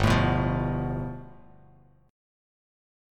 G9 Chord
Listen to G9 strummed